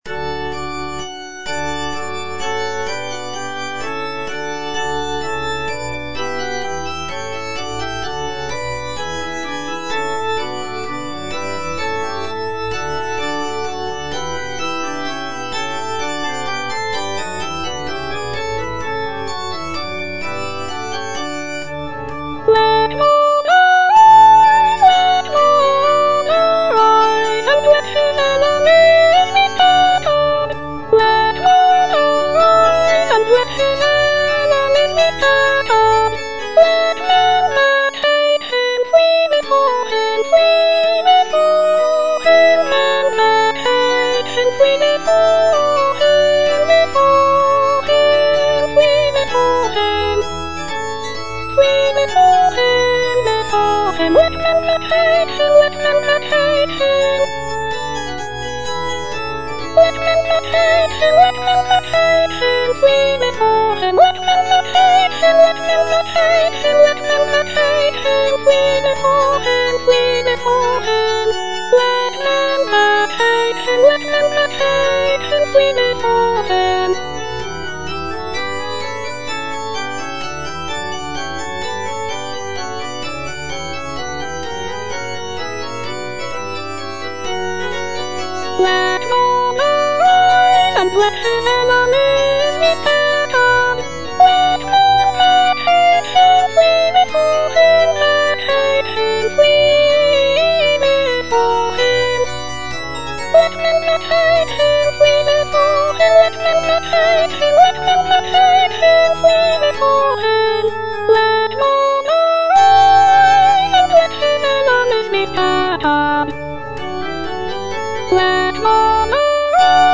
(soprano I) (Voice with metronome) Ads stop
sacred choral work